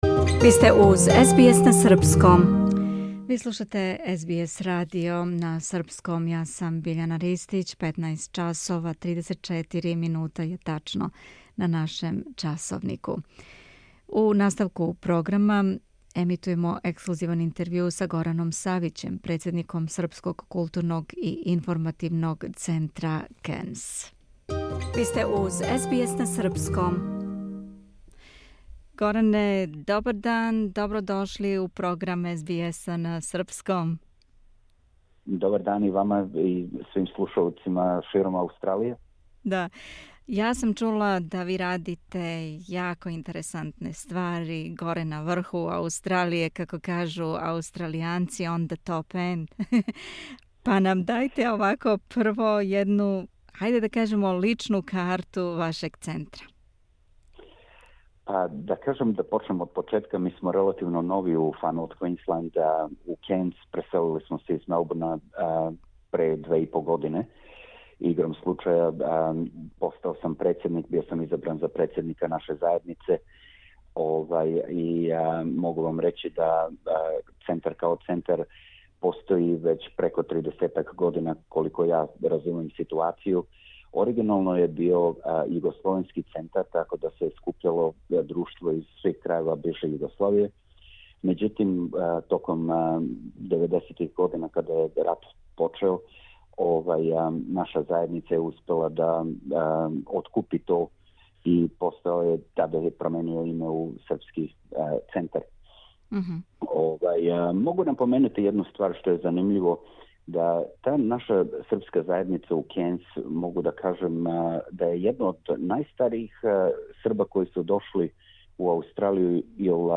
интервју